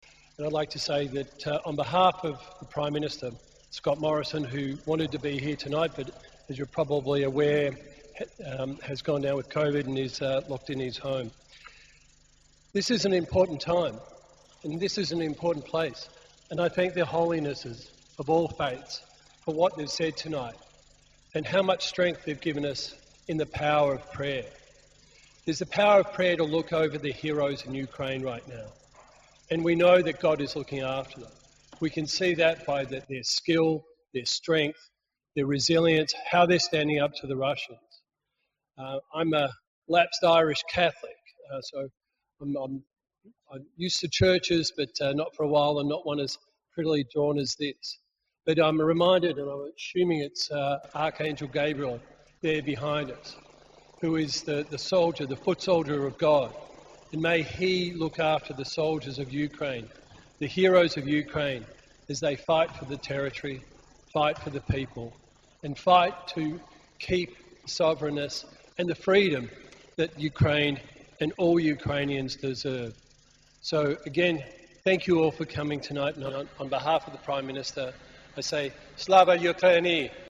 Senator David Van, Chair Australia-Ukraine Parliamentary Friendship Group. Prayer for peace in Ukraine. Ukrainian Catholic Cathedral of the Holy Apostles Peter and Paul.